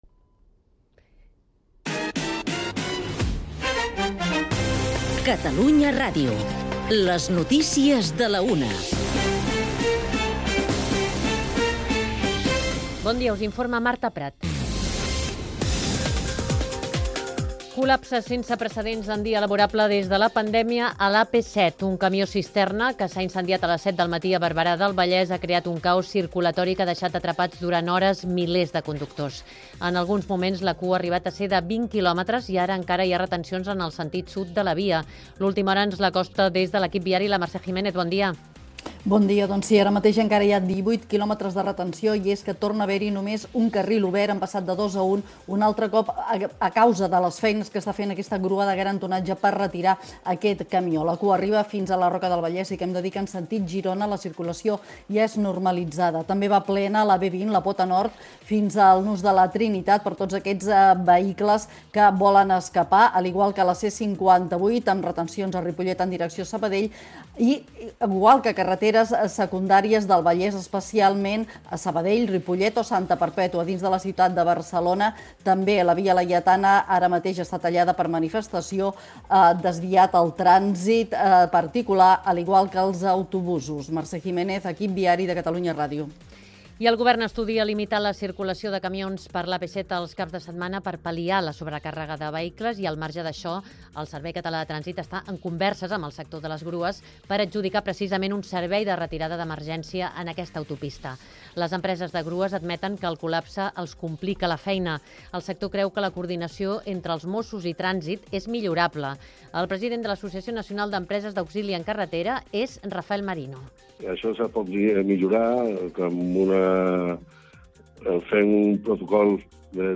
L’anunci del naixement d’INDÒMITA, el nou segell juvenil de Raig Verd, ha estat notícia avui a diversos mitjans: Catalunya Ràdio n’ha parlat al butlletí informatiu de les 13h, que podeu escoltar en l’ arxiu adjunt.